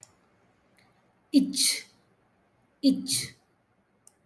ich